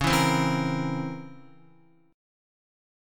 DbmM13 Chord
Listen to DbmM13 strummed